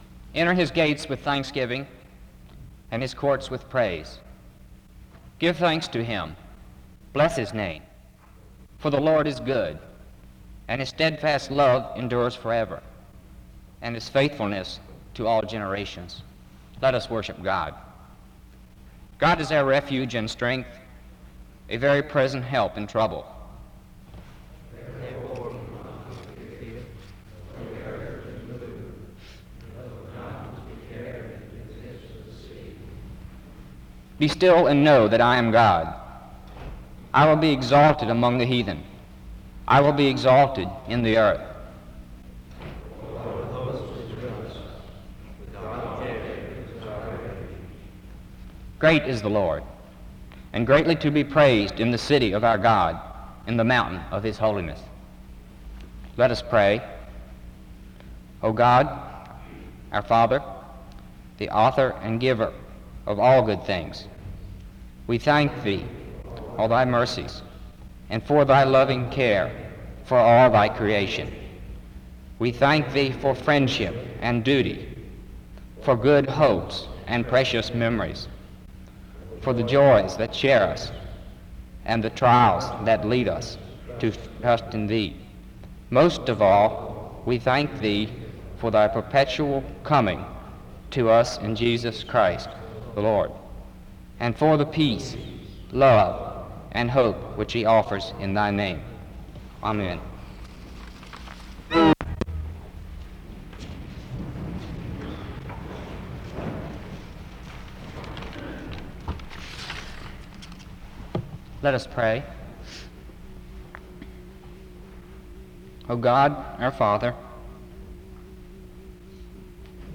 SEBTS_Chapel_Student_Service_1968-03-06.wav